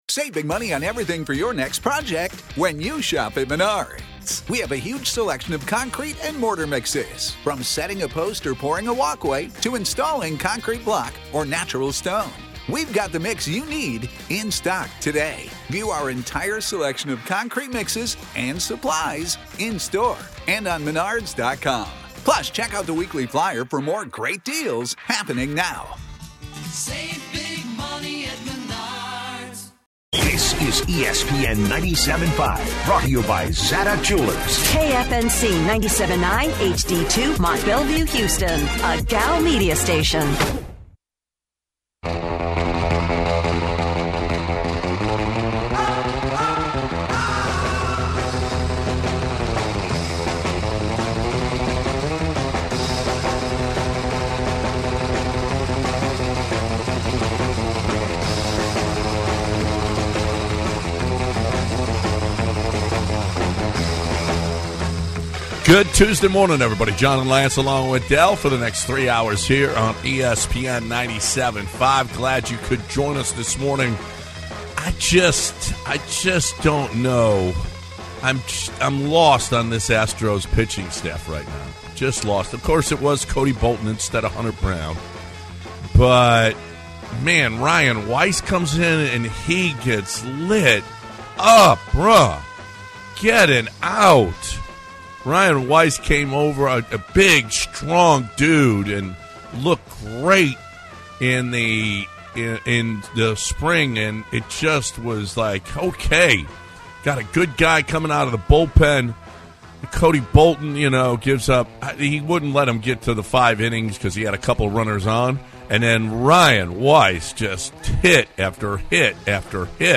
Gamblers Head Coach Kevin Sumlin chats about game winning FG